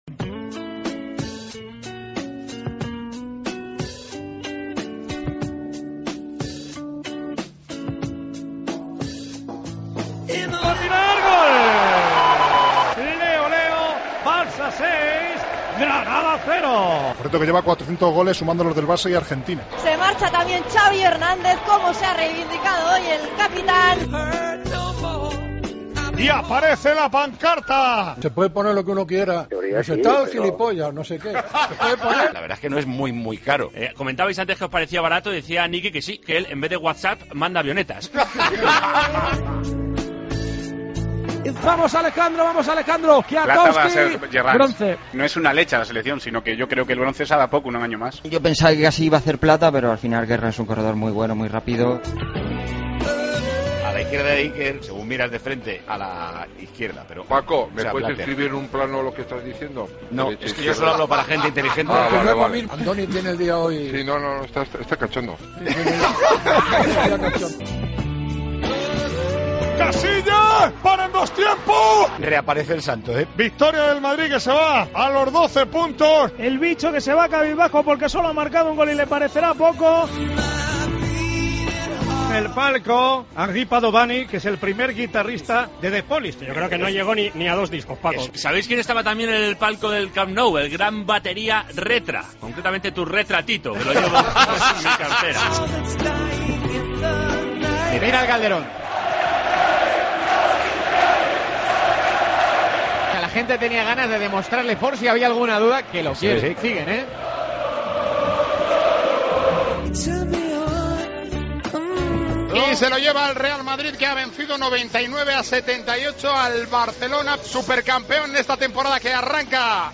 La avioneta que sobrevoló El Madrigal, el himno cordobés del 'Arcánfield' o la libranza de Dani Martínez son algunos de los sonidos de la sexta jornada en Tiempo de Juego.
Con Paco González, Manolo Lama y Juanma Castaño